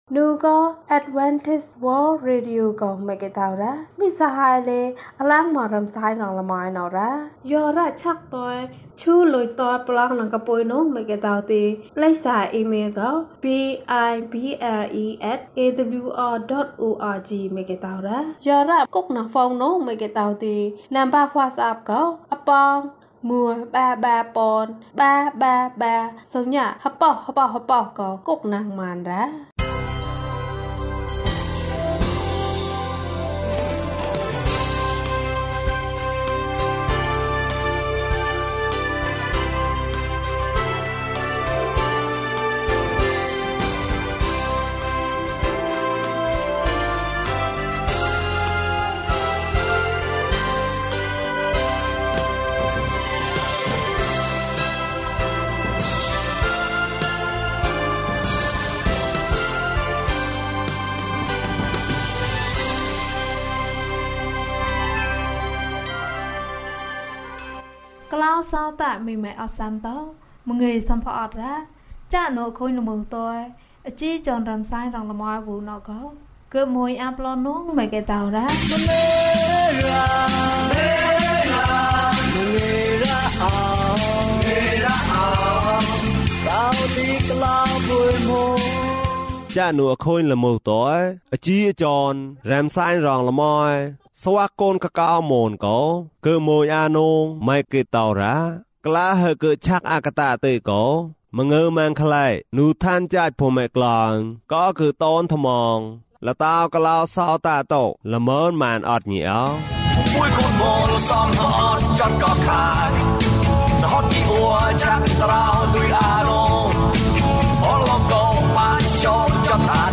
ခရစ်တော်ထံသို့ ခြေလှမ်း ၁၄။ ကျန်းမာခြင်းအကြောင်းအရာ။ ဓမ္မသီချင်း။ တရားဒေသနာ။